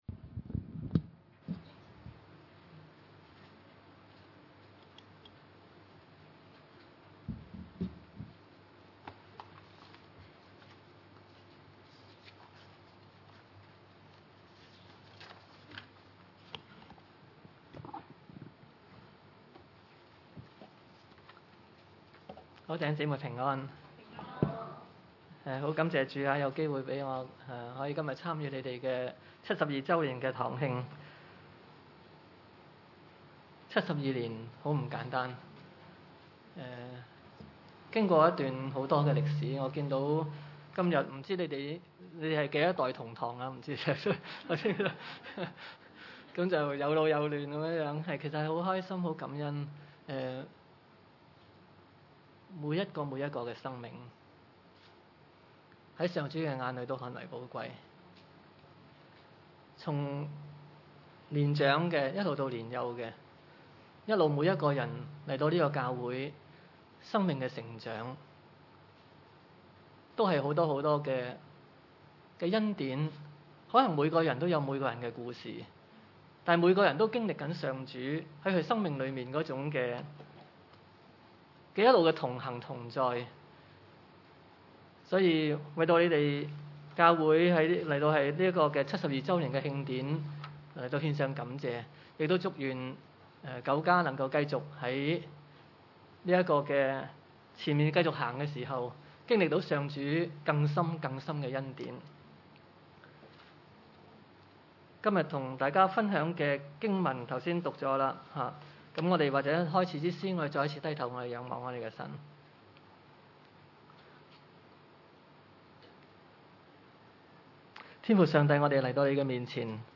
彼得前書4：7-11 崇拜類別: 主日午堂崇拜 7 萬物的結局近了。